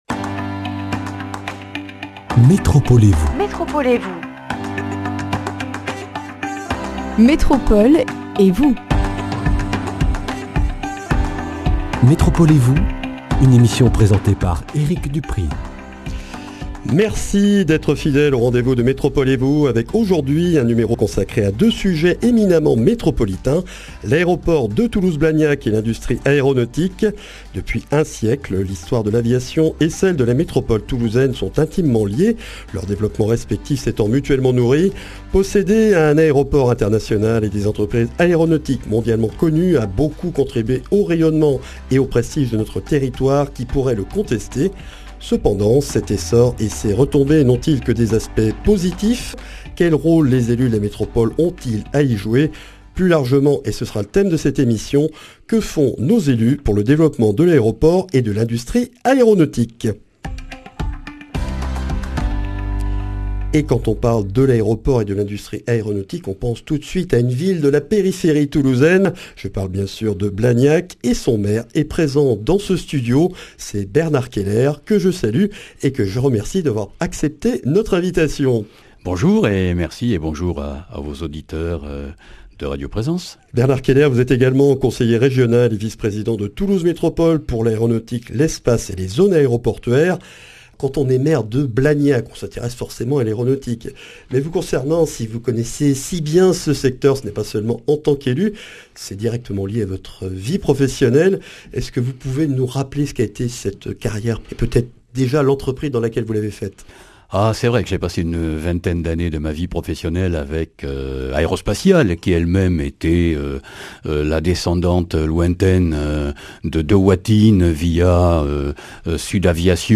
Une émission avec Bernard Keller, maire de Blagnac, conseiller régional d’Occitanie, Vice-président de Toulouse Métropole en charge de l’Aéronautique, des Espaces et Plateformes aéroportuaires pour faire le point sur l’avenir de l’aéroport et de l’industrie aéronautique dans la métropole toulousaine.